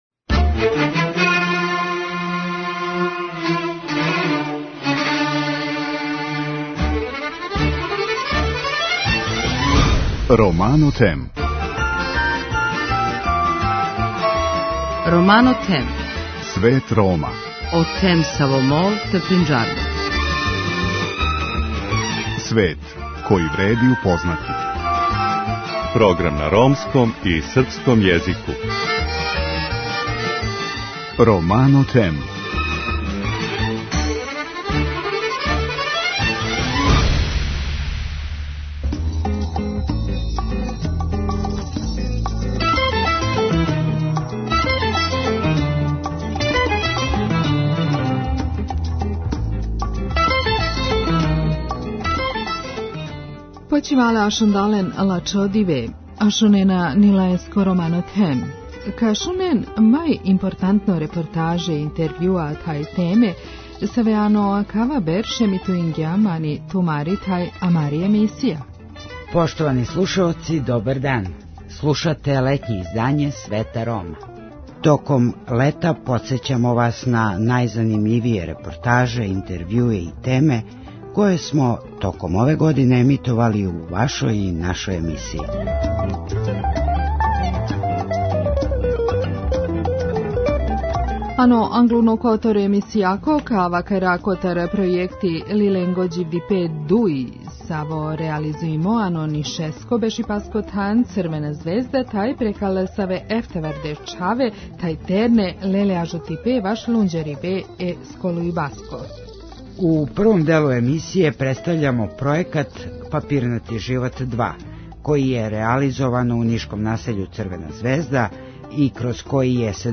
Емисија Свет Рома од 16. јула до 2. септембра емитује најзанимљивије репортаже, интервјуе и теме које смо током ове године емитовали у вашој и нашој емисији.